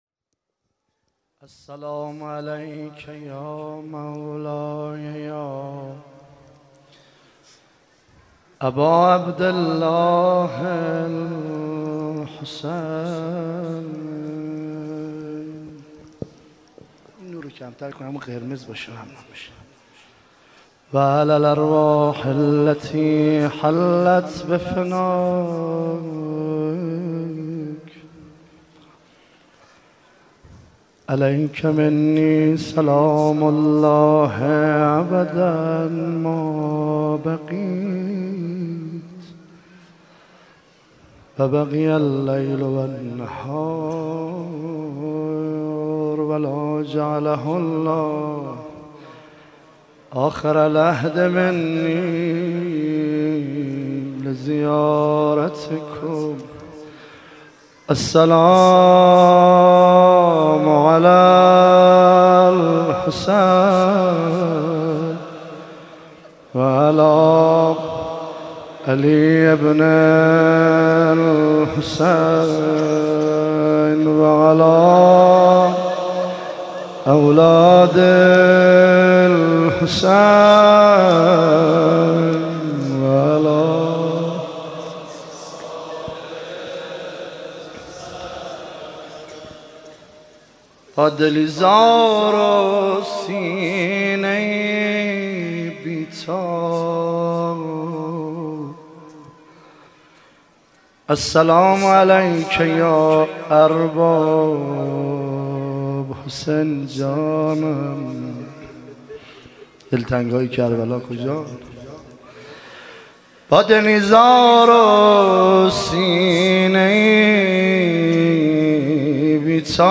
عنوان شب بیست و دوم محرم الحرام ۱۳۹۸
روضه